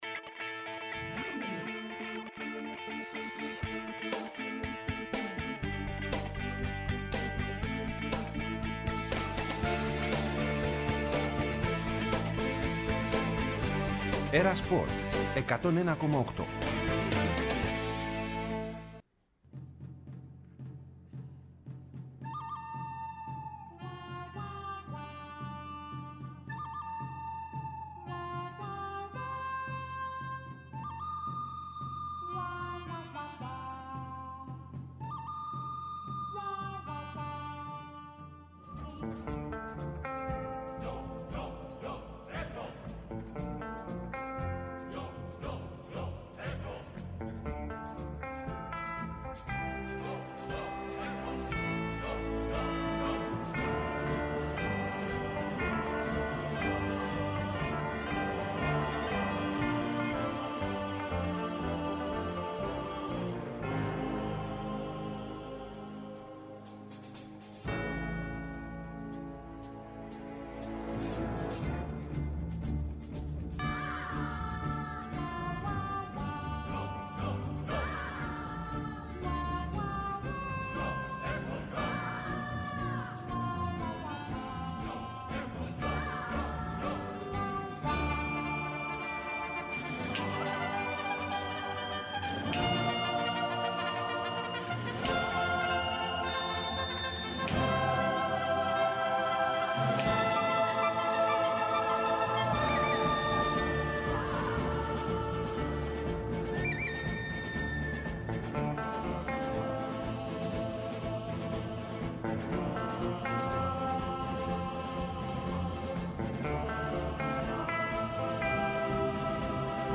Ενημέρωση από τους ρεπόρτερ του Ολυμπιακού, του Παναθηναϊκού, της ΑΕΚ, του ΠΑΟΚ και του Άρη.